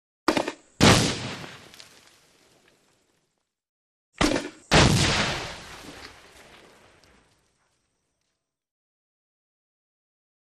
Grenade Launcher ( 2x ); Two Grenade Launcher Fires With Hollow Boom And Resulting Explosion With Debris Fall. Close Perspective Launch And Fire.